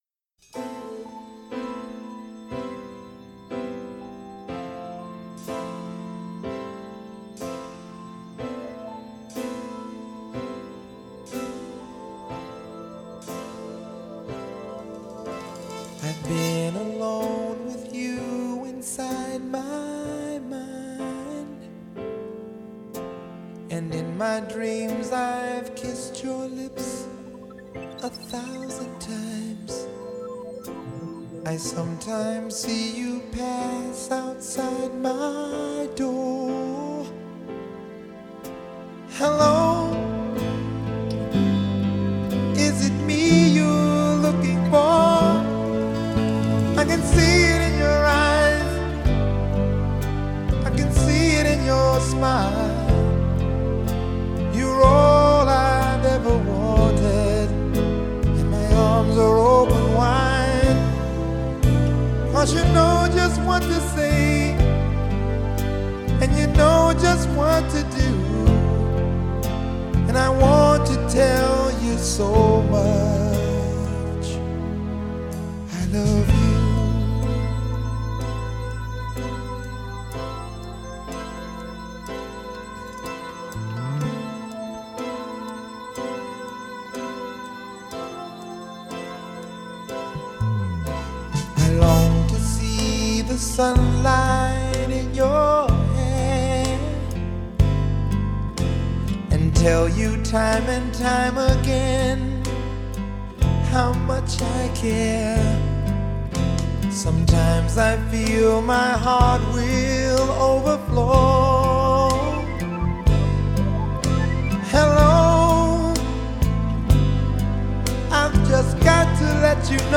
Genre: Pop
Acoustic Guitar
Bass Guitar
Electric Piano [Fender Rhodes]
Synthesizer [Synthesizers]